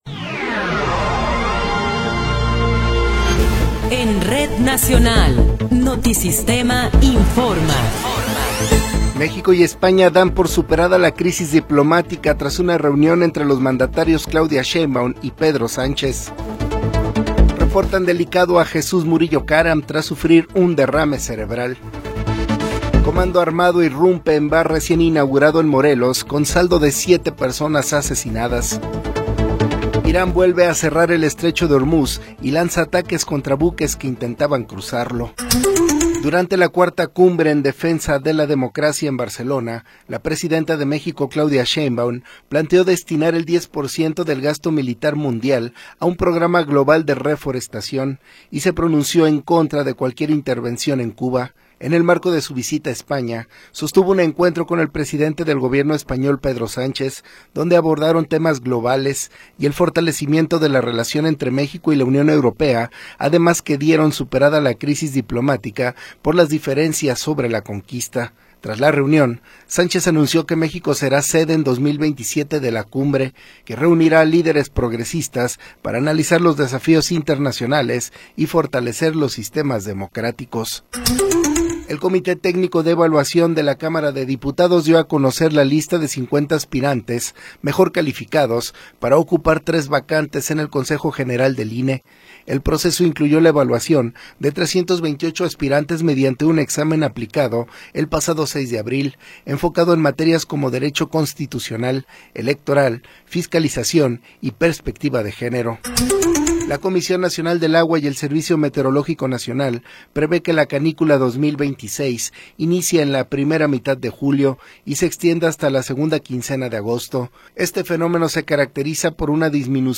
Noticiero 20 hrs. – 18 de Abril de 2026
Resumen informativo Notisistema, la mejor y más completa información cada hora en la hora.